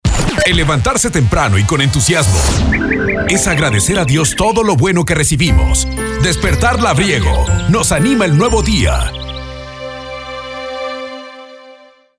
Aquí encontrará lo que hace único a nuestro amanecer: la música campesina, la carranga, los sonidos populares y la mexicana que alegran el alma; el estado del clima para empezar la jornada bien informado; y por supuesto, los saludos y mensajes que acercan a las familias y comunidades en cada rincón del país.